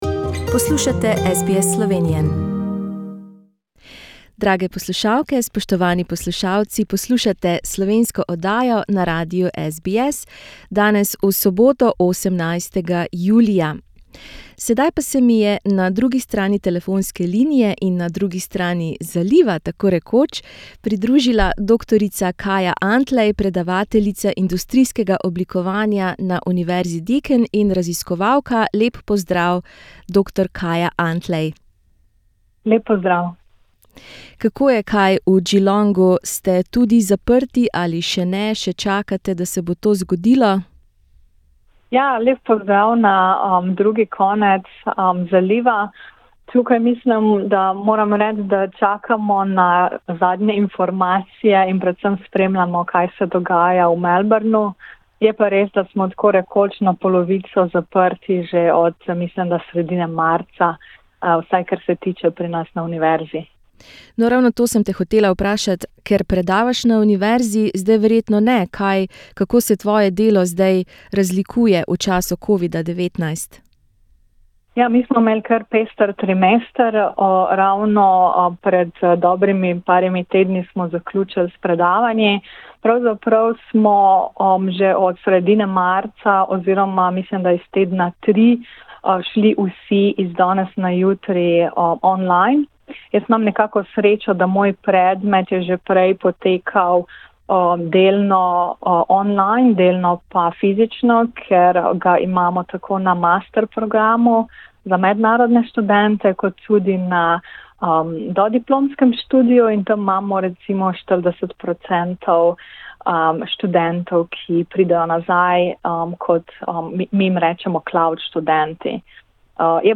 Pogovarjali sva se o virtualni resničnosti, o njenem delu na univerzi in o tem, kako se mlajša generacija Slovencev v Avstraliji povezuje v času COVIDa-19.